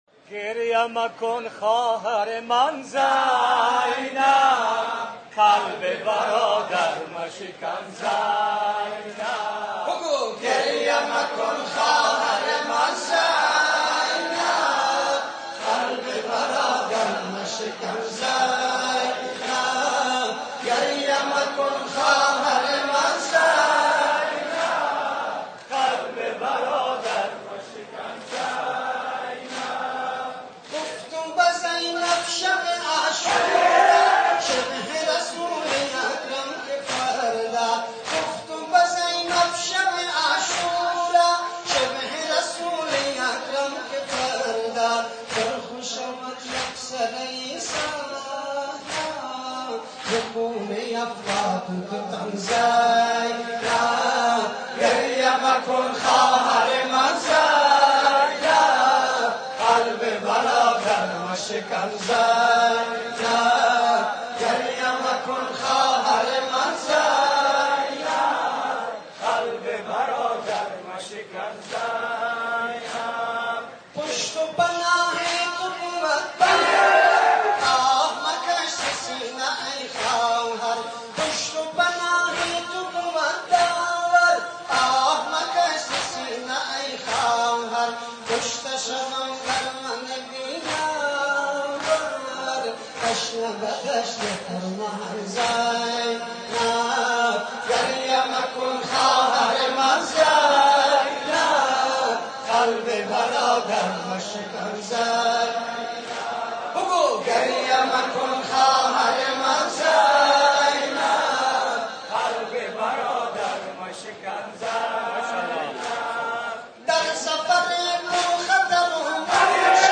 برچسب ها: شیعیان افغانستان نوحه محرم 1403 مشهد